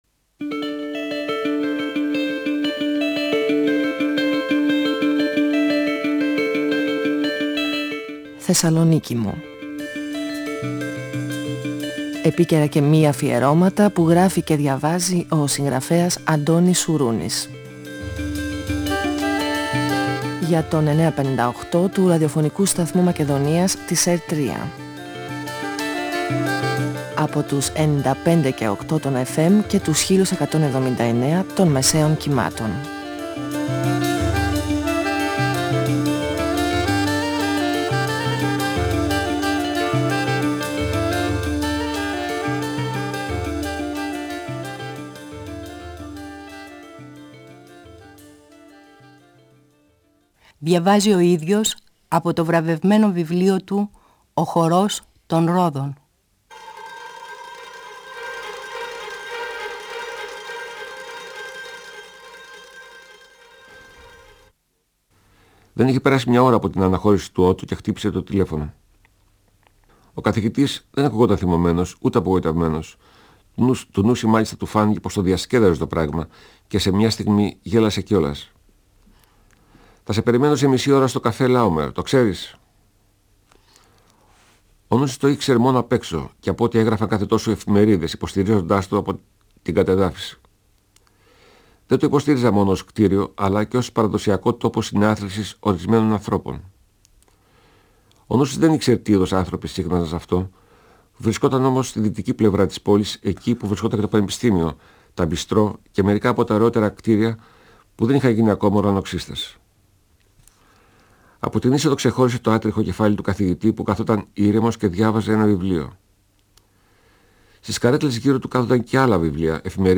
Ο συγγραφέας Αντώνης Σουρούνης (1942-2016) διαβάζει το πρώτο κεφάλαιο από το βιβλίο του «Ο χορός των ρόδων», εκδ. Καστανιώτη, 1994. Ο Νούσης συναντά τον Καθηγητή, για να συζητήσουν για τους τυχερούς αριθμούς από τις ρουλέτες του καζίνου. Ο Ρολφ Τσίβιτ, παίκτης του καζίνου κι αυτός, δανείζεται χρήματα από τον Ιταλό, τον Σαλβατόρε, αλλά δυσκολεύεται να τα επιστρέψει.